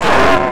PAIN50_2.WAV